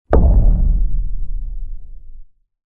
Звуки кометы
Звук сильного удара метеорита при падении на Землю или Луну